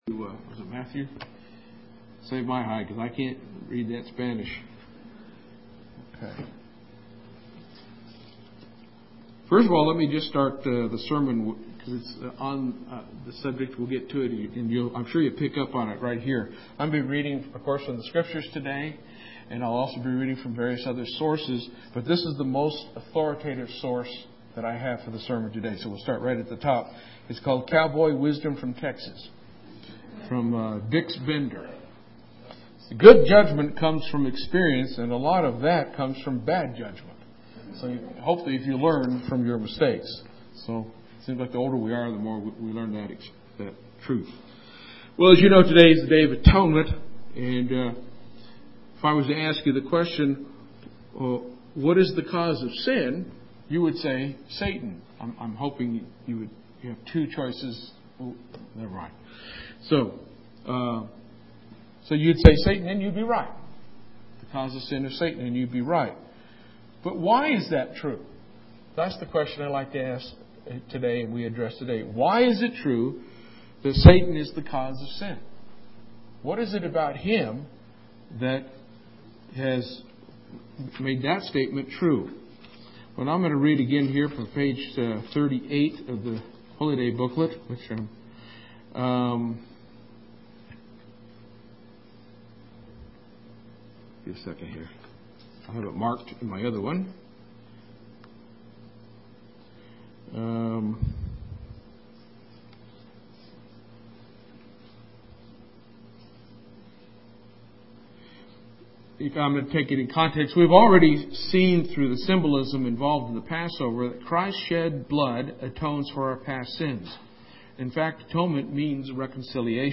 Given in Lubbock, TX
UCG Sermon Studying the bible?